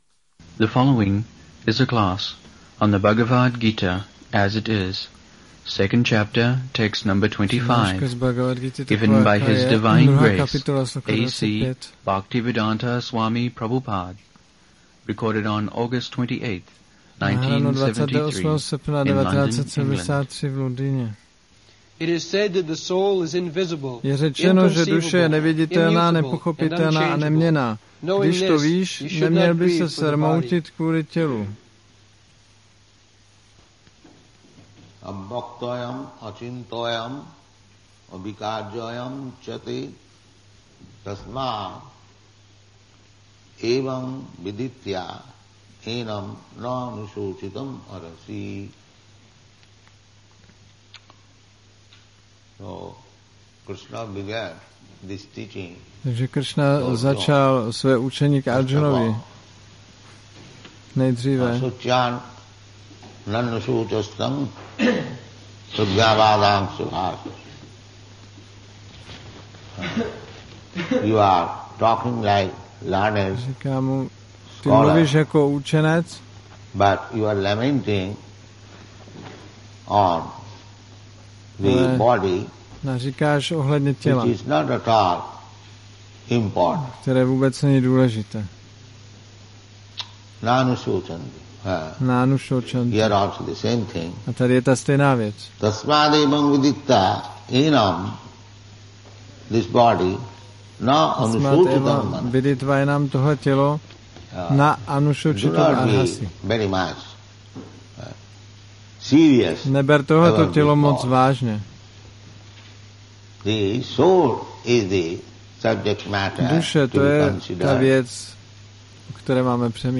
1973-08-28-ACPP Šríla Prabhupáda – Přednáška BG-2.25 London